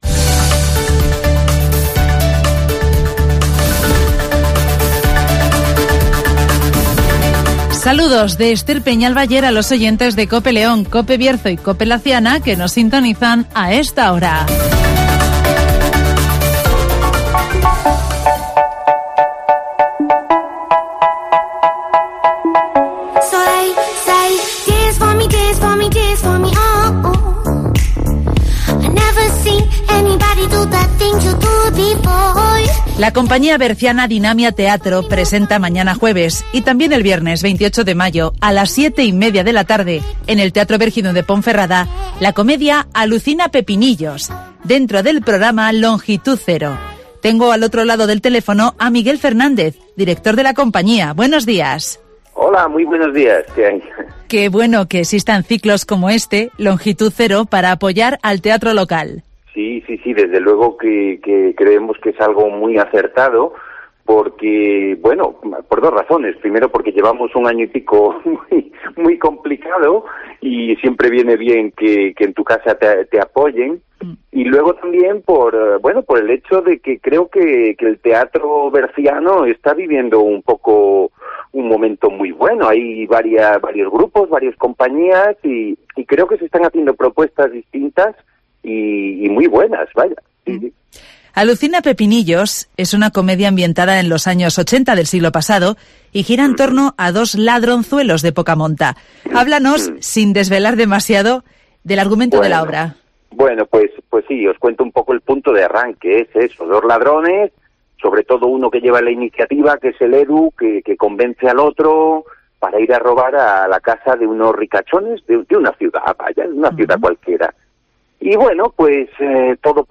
Alucina Pepinillos de la compañía berciana Dinamia Teatro llega al Teatro Bergidum de Ponferrada (Entrevista